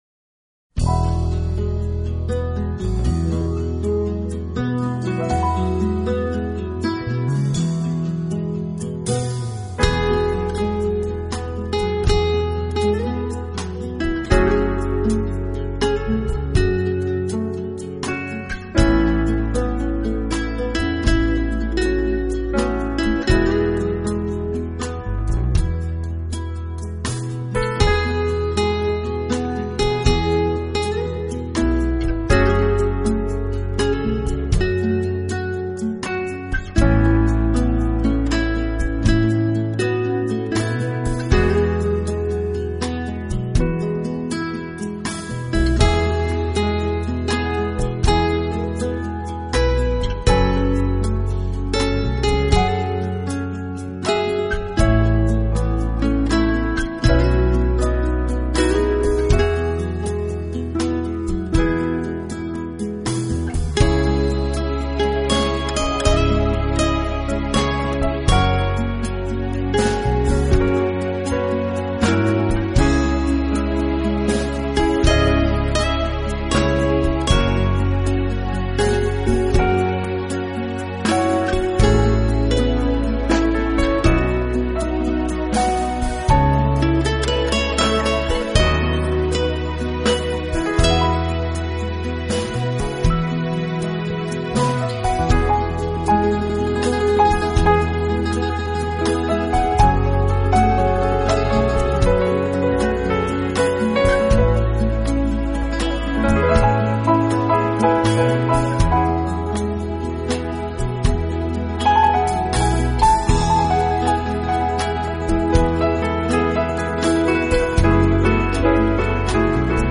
拉丁吉他
这是一张拉丁风格吉他曲专辑。